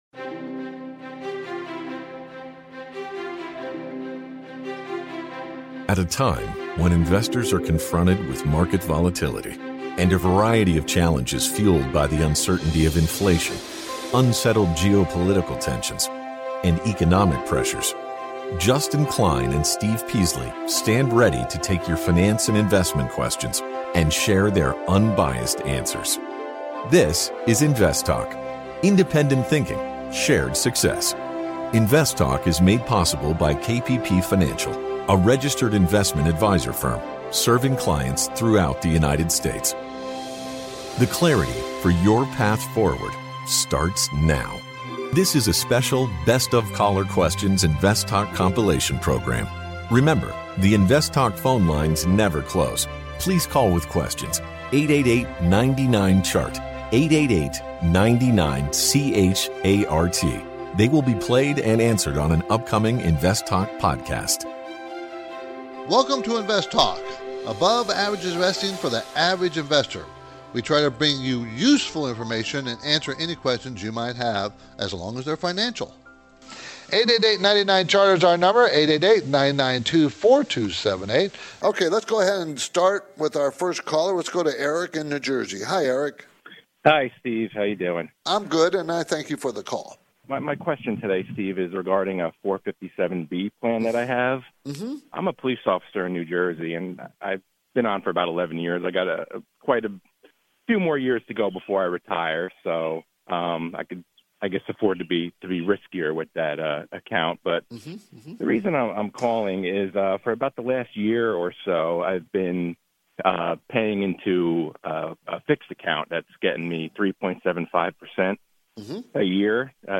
In this compilation program
field a variety of finance and investment questions from callers across the United States and around the world.